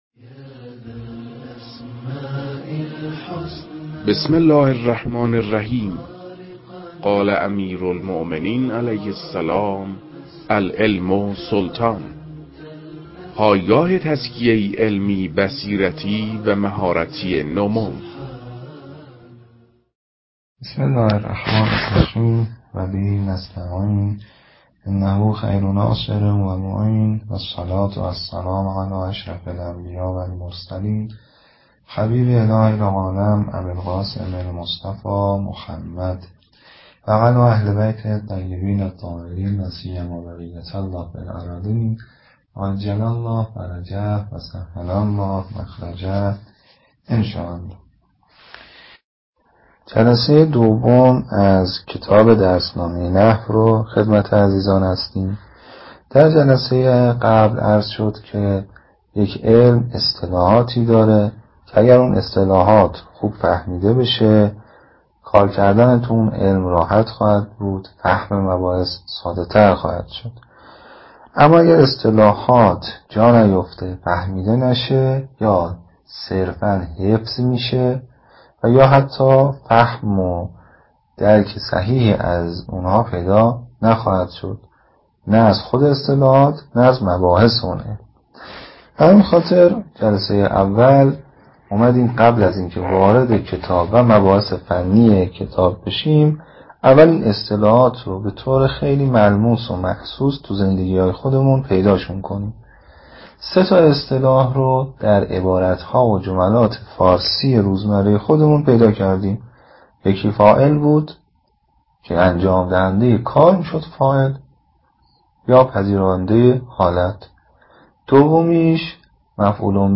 در تدریس این کتاب- با توجه به سطح کتاب- سعی شده تا مطالب به صورت روان و در حد آشنایی ارائه شود.